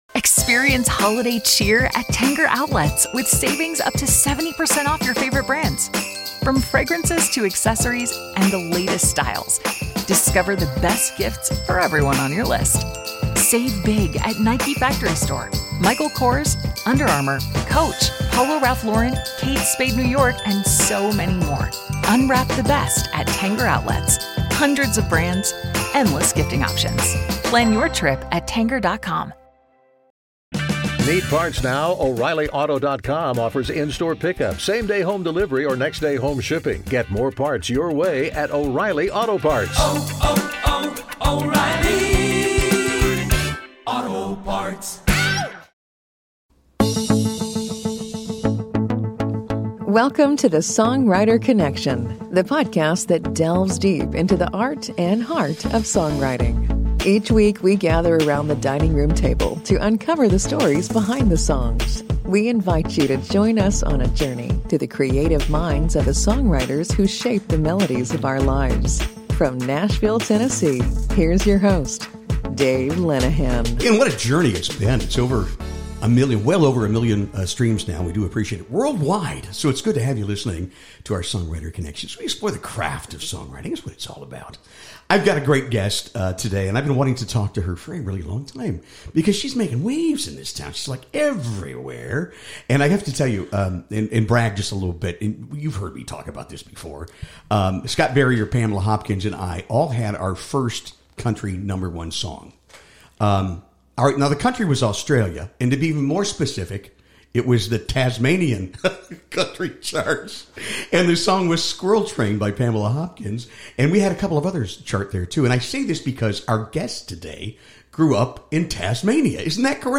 In this intimate conversation, she shares her journey from Australia to the heart of country music, her experiences playing iconic venues like the Bluebird Cafe and Listening Room, and her adventures performing around the globe.